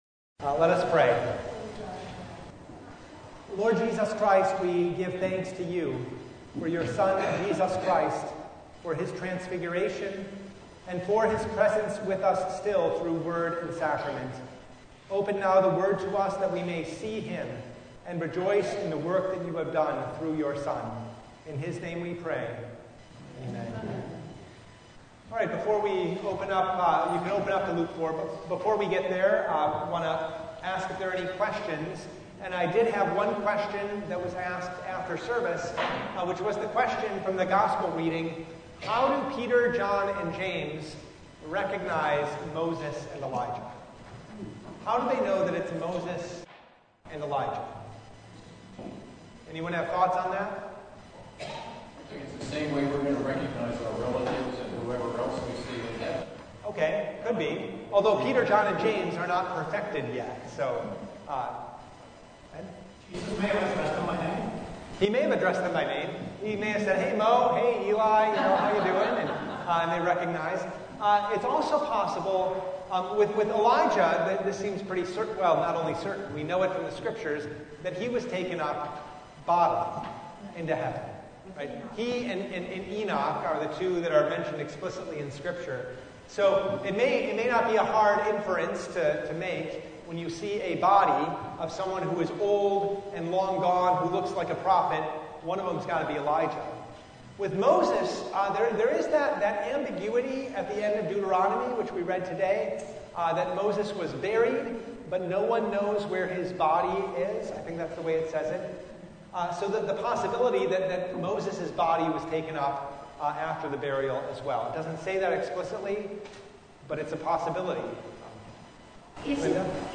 Passage: Luke 4:1-13 Service Type: Bible Study